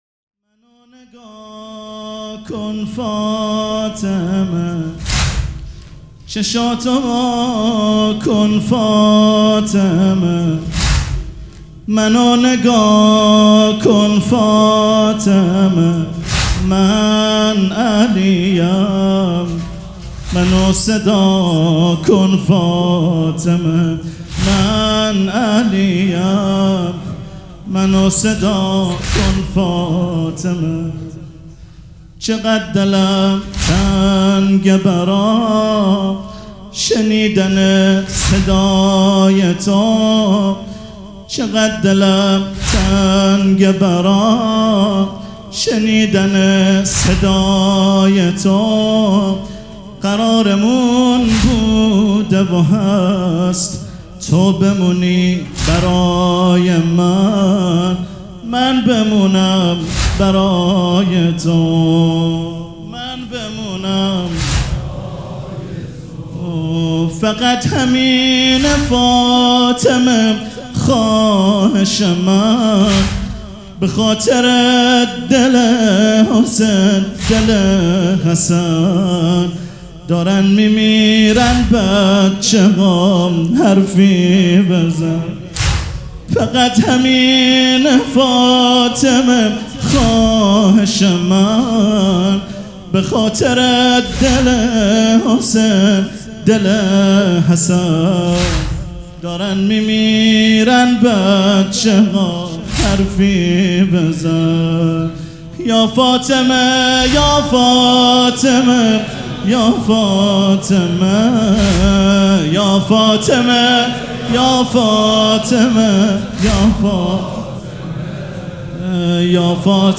مداح
مراسم عزاداری شب شهادت حضرت زهرا (س)